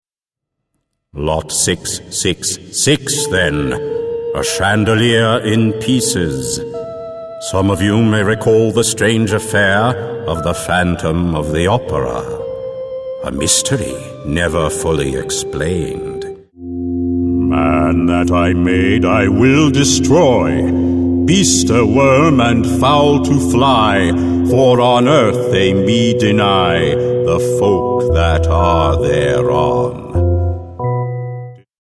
Native English Speaker; bass voice; technical, medical or documentary narration; excellent actor; short-notice access to studios
mid-atlantic
Sprechprobe: Sonstiges (Muttersprache):